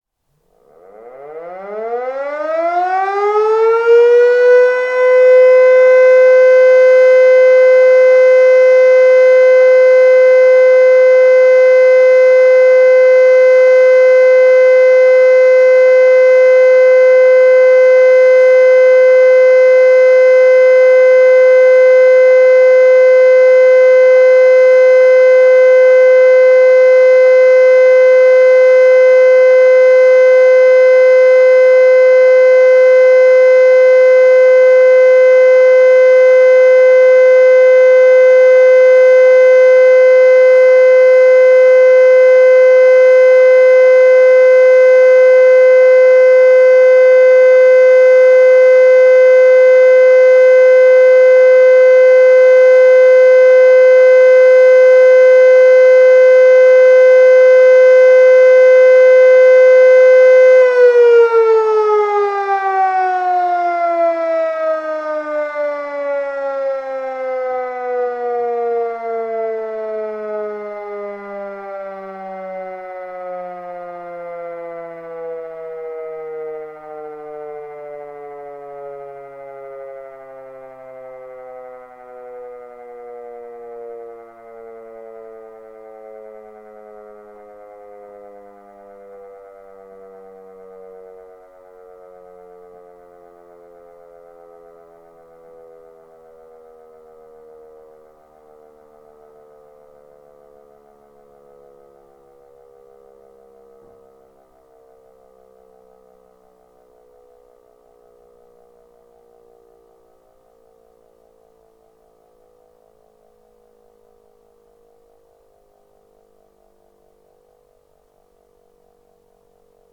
Sirenenalarm - FFW-Gross-Rohrheim
• Entwarnung
1 Min. Dauerton
Entwarnung-1Min-Dauerton.mp3